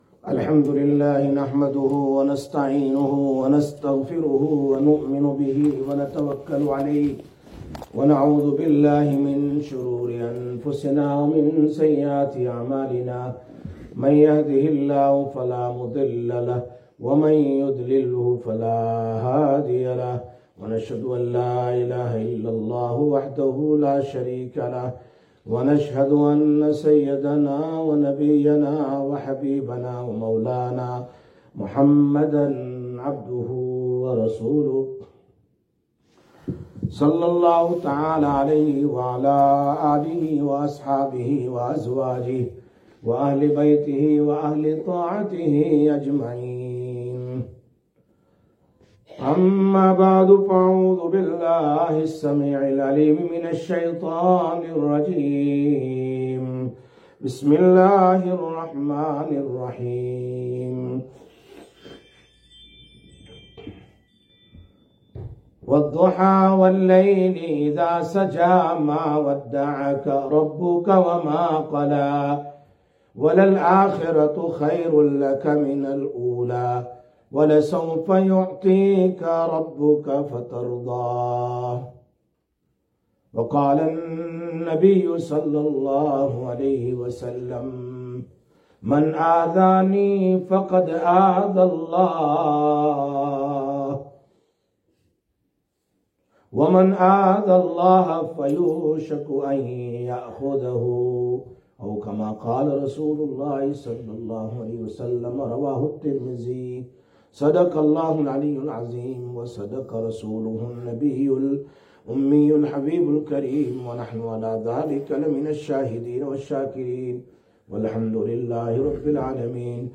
06/12/2024 Jumma Bayan, Masjid Quba